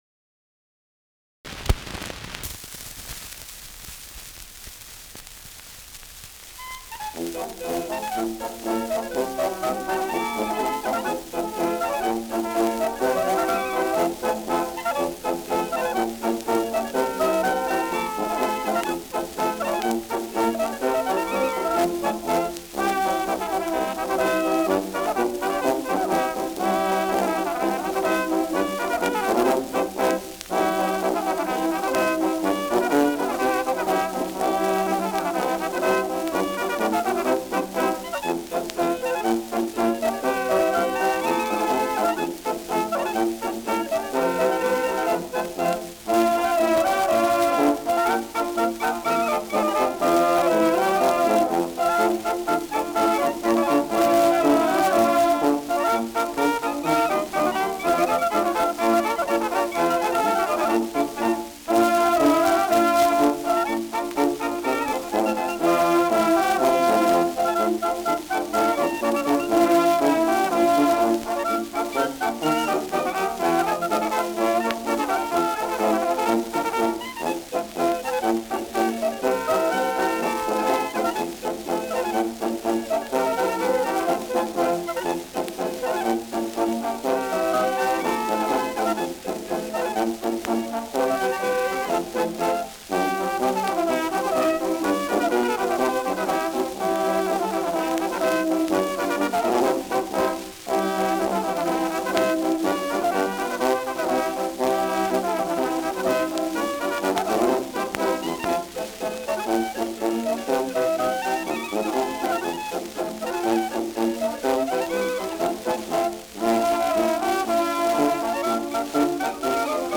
Schellackplatte
Stärkeres Grundrauschen : Gelegentlich leichtes Knacken
Kapelle Dorn, Happurg (Interpretation)